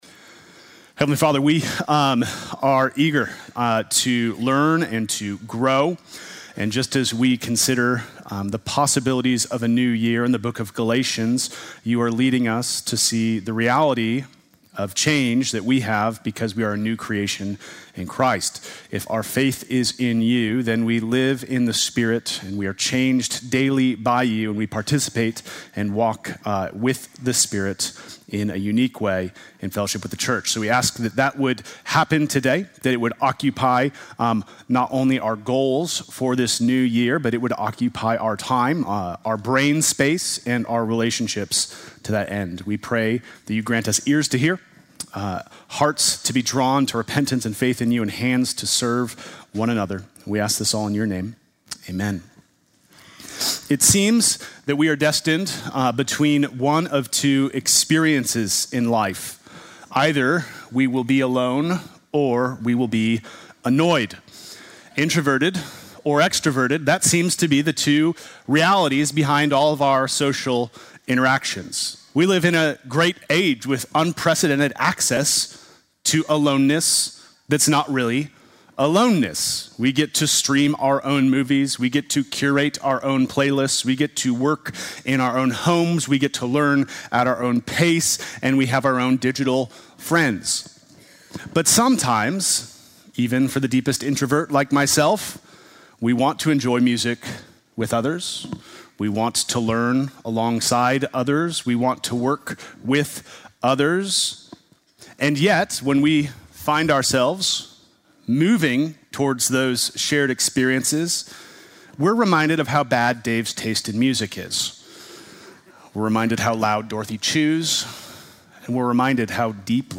Sunday morning message January 4